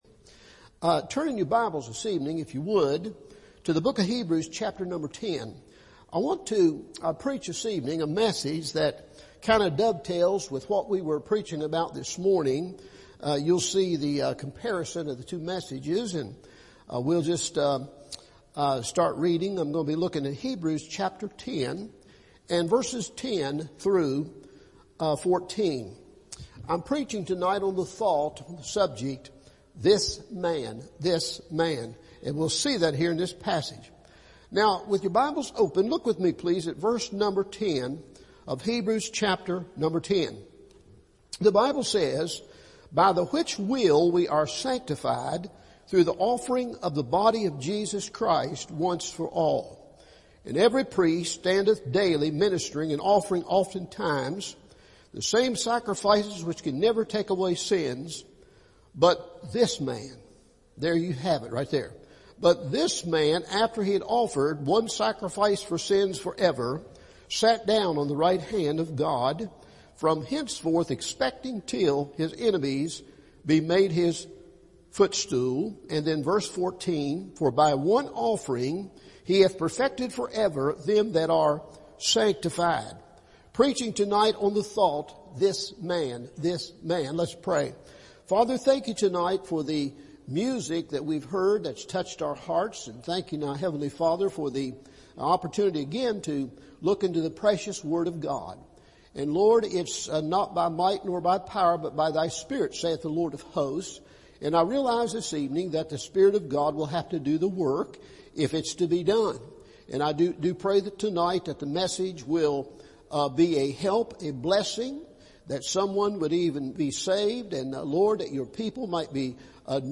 This Man – Evening Service